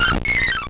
Cri de Joliflor dans Pokémon Or et Argent.